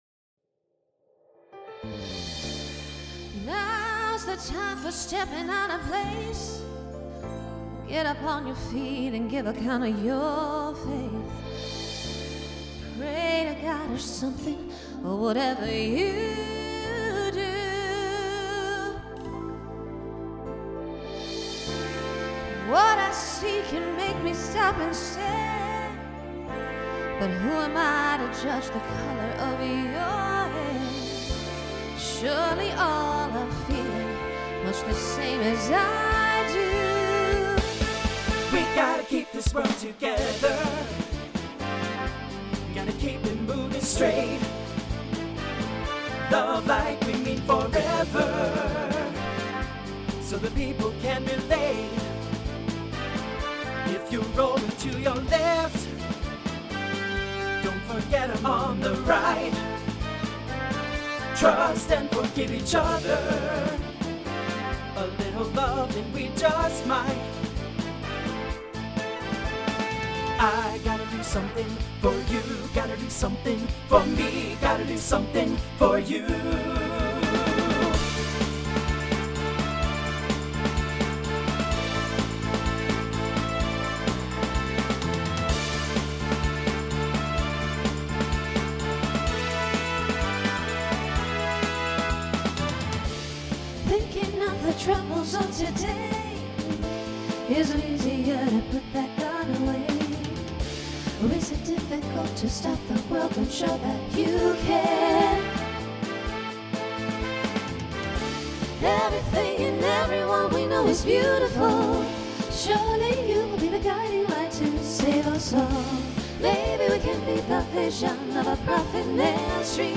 TTB/SSA
Voicing Mixed Instrumental combo
Pop/Dance